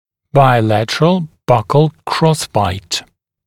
[baɪ’lætərəl ˈbʌkl ˈkrɔsbaɪt][бай’лэтэрэл ˈбакл ˈкросбайт]двусторонний боковой перекрестный прикус